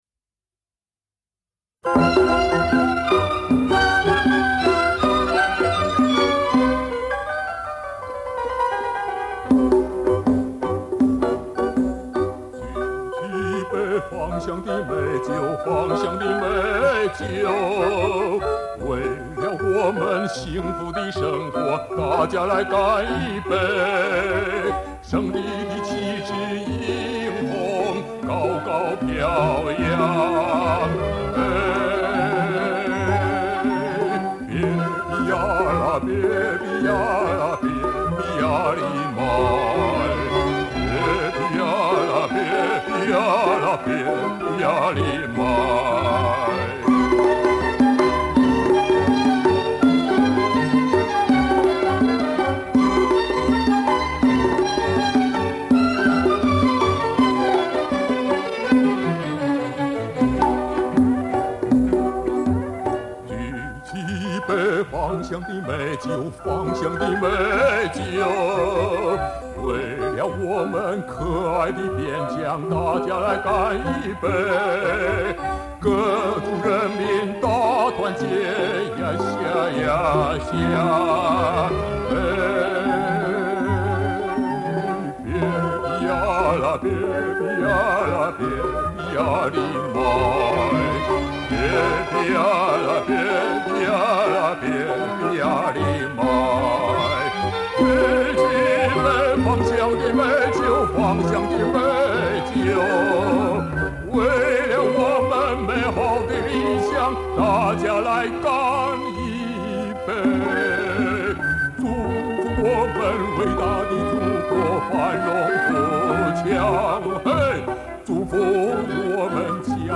乌孜别克民歌